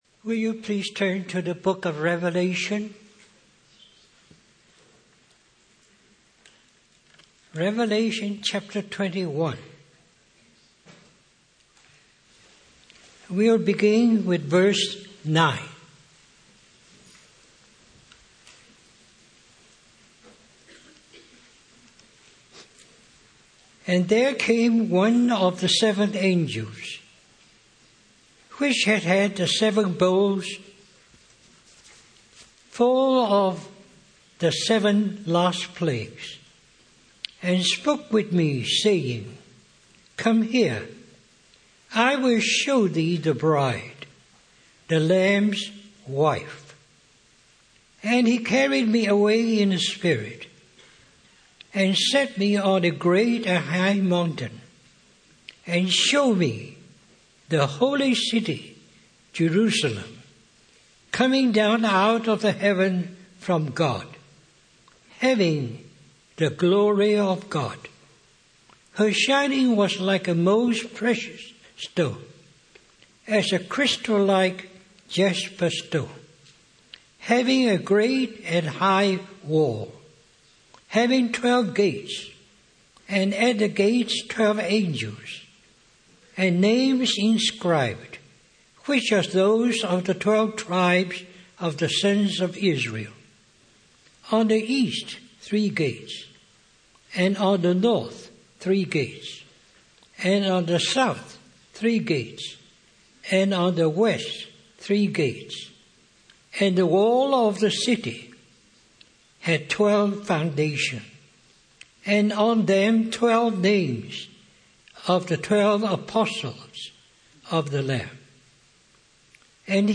Christian Family Conference